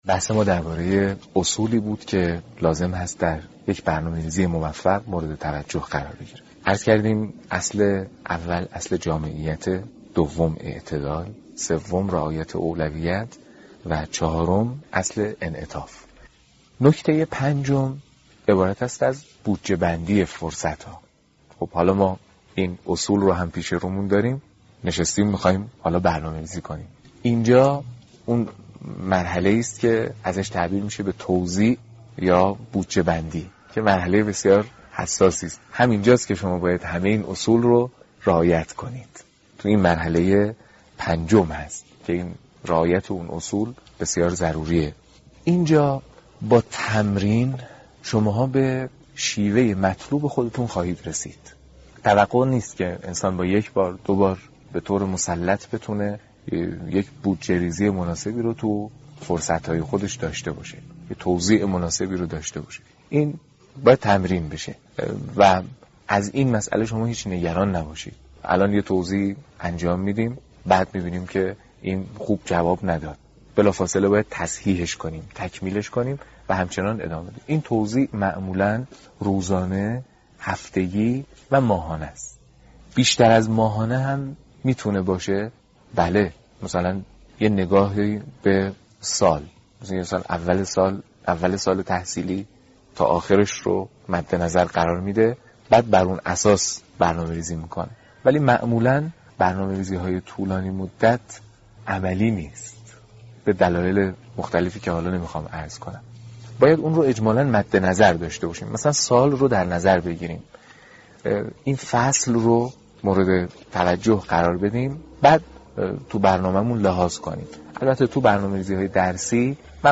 دانلود سومین جلسه از بیانات حجت الاسلام محمدجواد حاج علی اکبری با عنوان «مدیریت زمان»